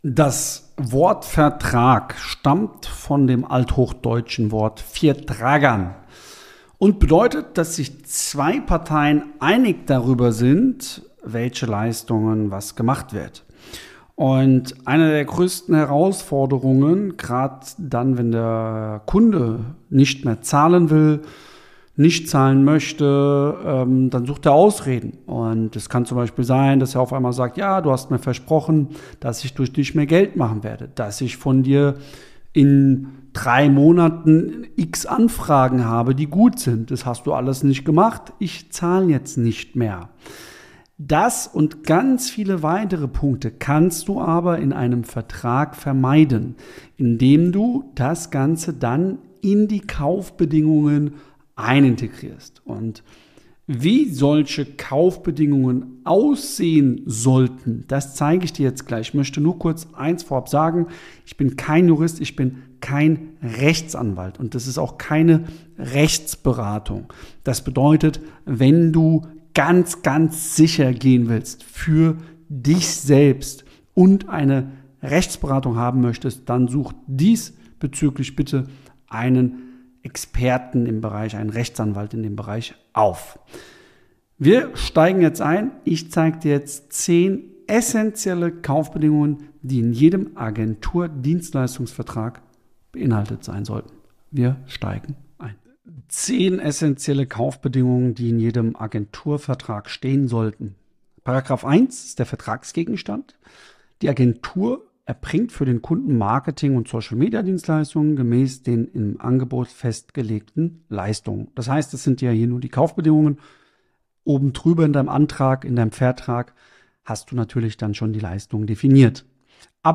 Diese Episode des Podcasts ist ein Audiomitschnitt aus dem YouTube Video https